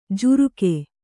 ♪ juruke